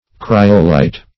kryolite - definition of kryolite - synonyms, pronunciation, spelling from Free Dictionary Search Result for " kryolite" : The Collaborative International Dictionary of English v.0.48: Kryolite \Kry"o*lite\, n. (Min.)